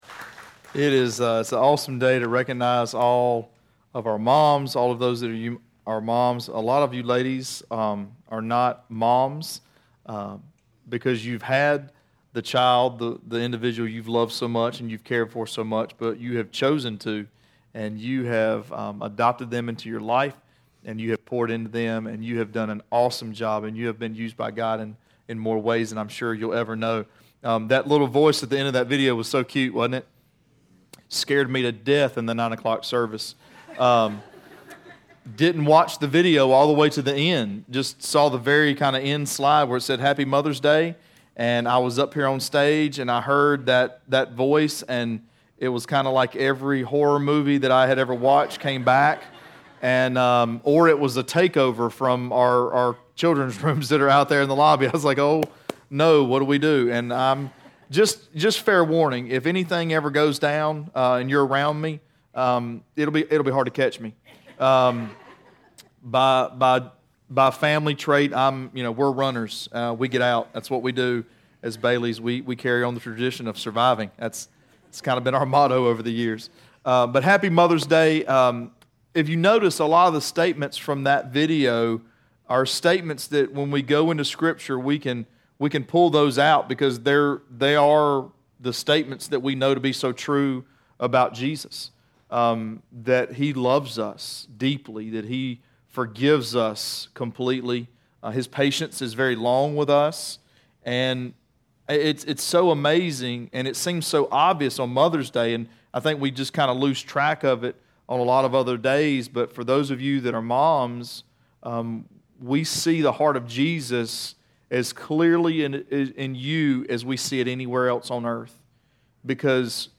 Sermons Archive - Page 37 of 60 - REEDY FORK COMMUNITY CHURCH